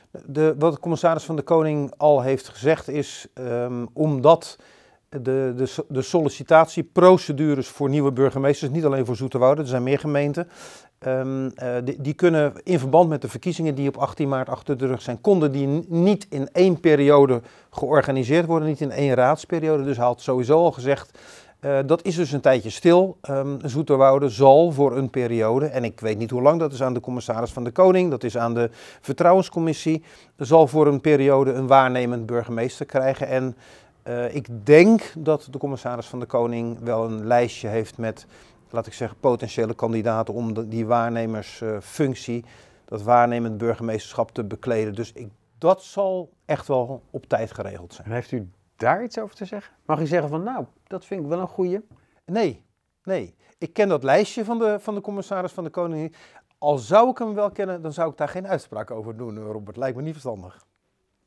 Burgemeester Fred van Trigt legt kort uit waarom er een waarnemer in Zoeterwoude komt: